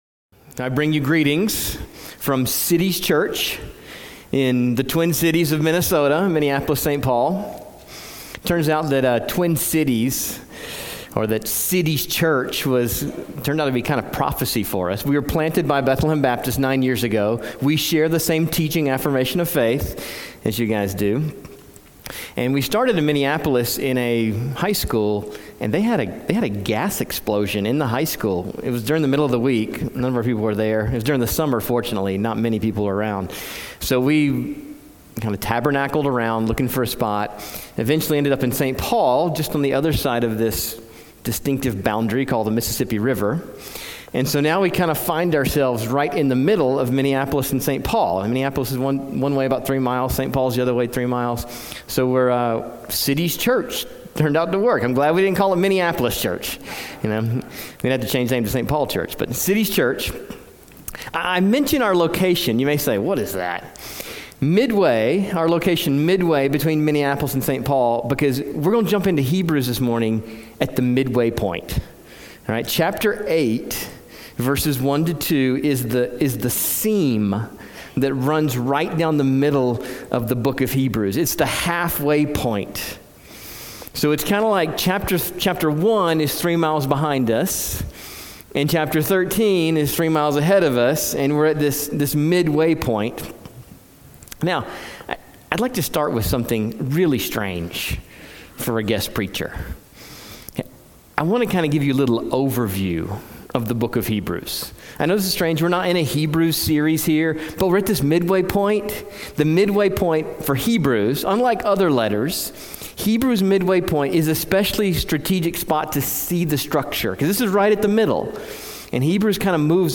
Calvary Grace Church | Calgary